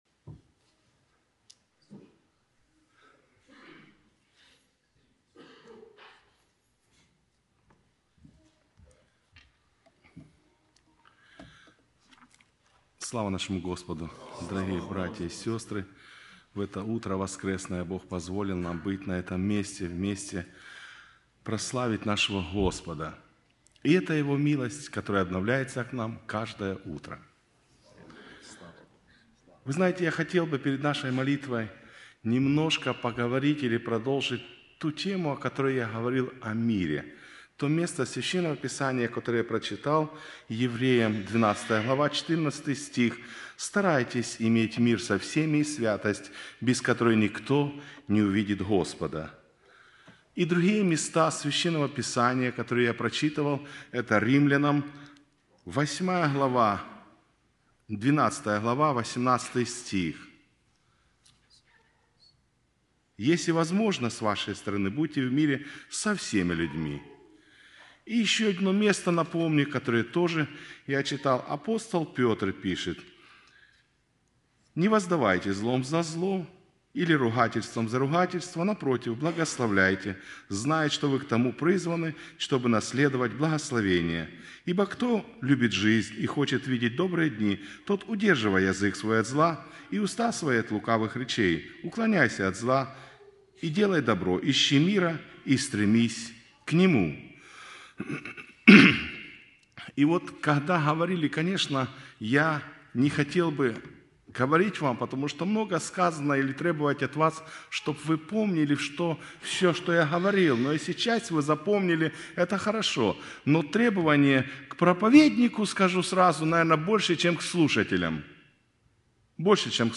Preacher 2